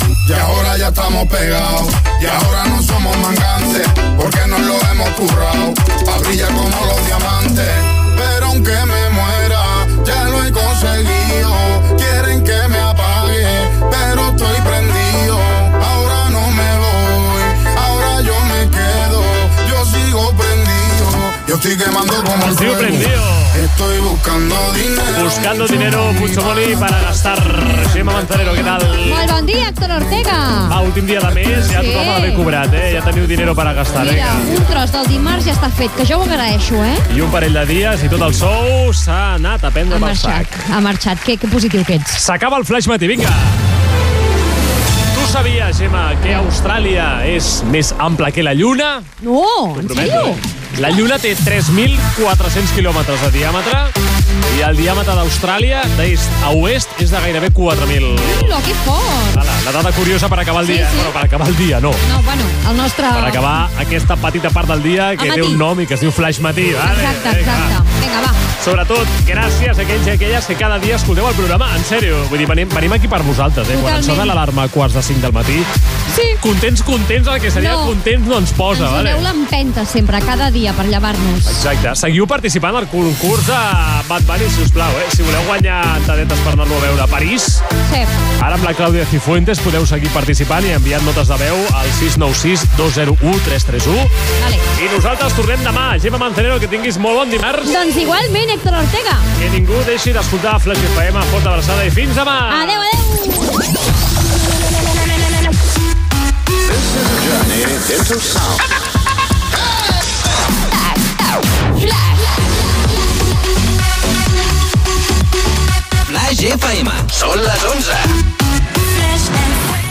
Tema musical, comentari sobre la fi del mes i el sou, curiositat geogràfica sobre Austràlia, comiat i indicatiu de l'emissora.
Musical